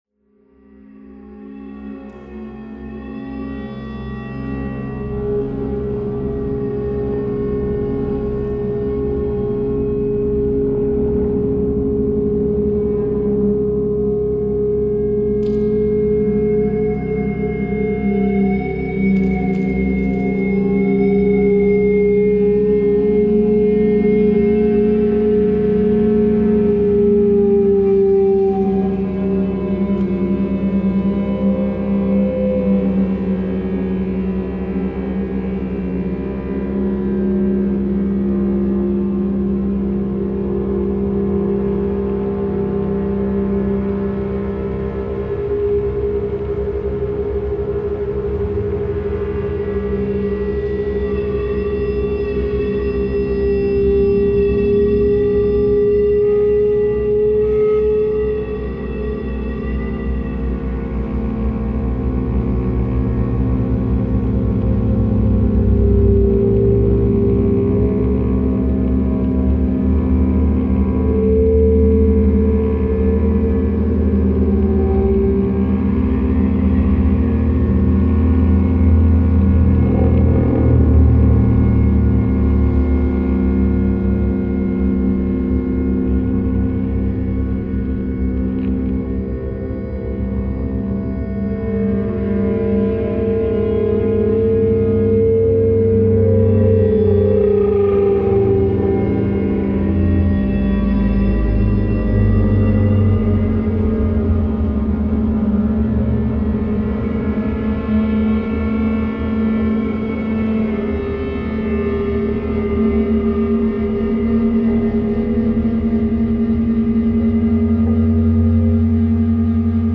This LP was recorded in a Berlin church